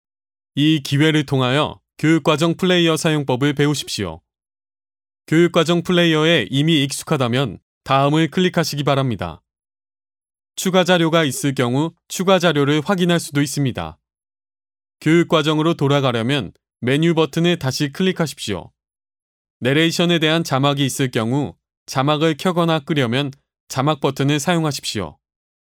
韩语样音试听下载
韩语配音员（男1）